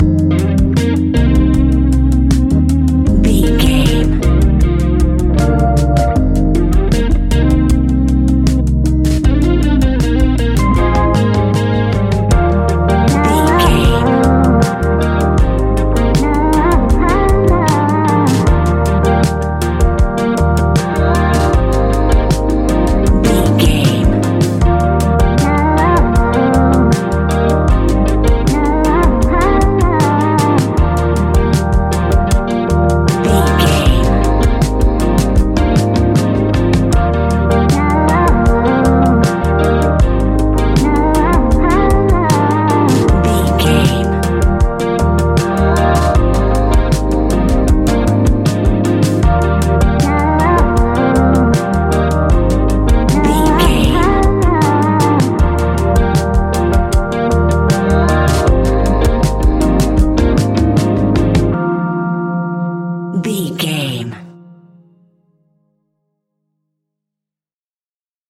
Ionian/Major
D♯
laid back
Lounge
sparse
new age
chilled electronica
ambient
atmospheric
instrumentals